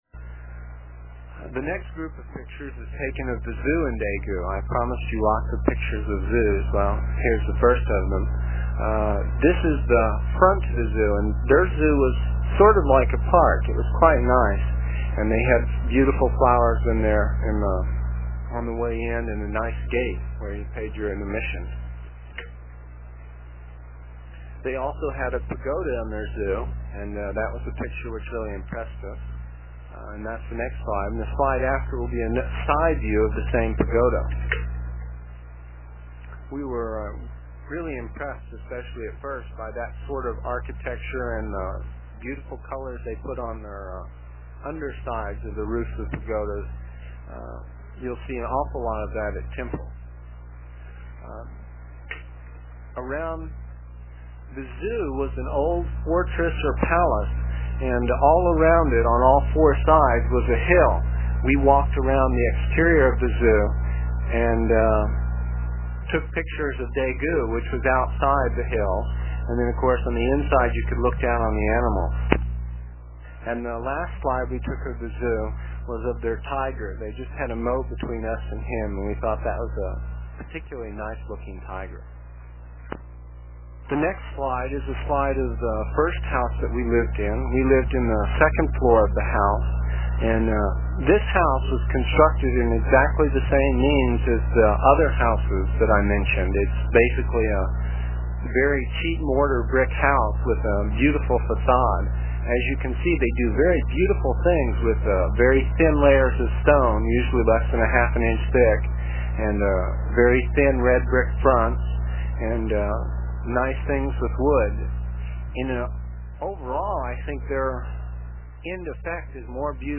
It is from the cassette tapes we made almost thirty years ago. I was pretty long winded (no rehearsals or editting and tapes were cheap) and the section for this page is about six minutes and will take about two minutes to download with a dial up connection.